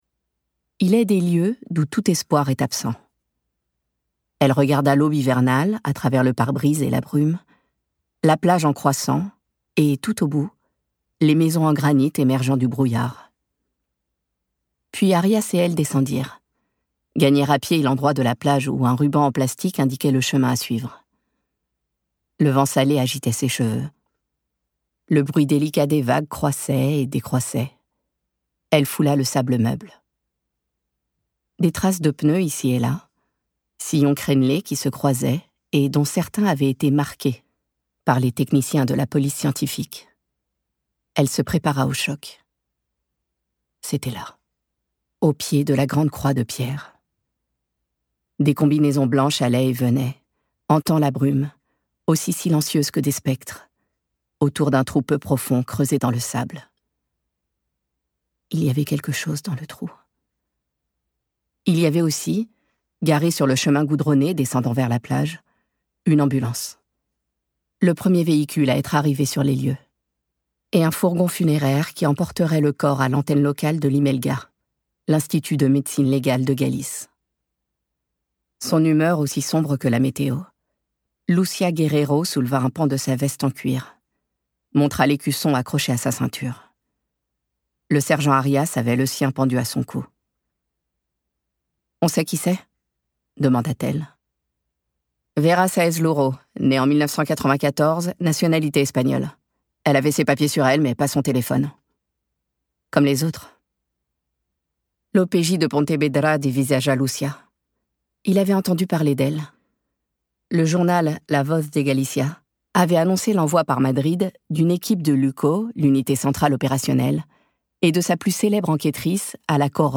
0% Extrait gratuit Les effacées